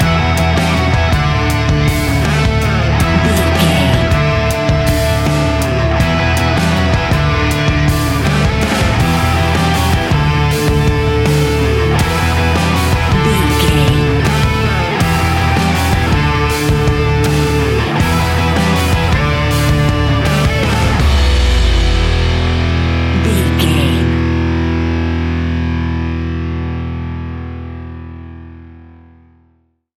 Ionian/Major
E♭
hard rock
heavy rock
distortion